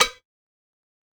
Perc (15).wav